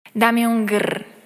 trendl dame un grr Meme Sound Effect
This sound is perfect for adding humor, surprise, or dramatic timing to your content.